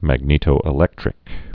(măg-nētō-ĭ-lĕktrĭk)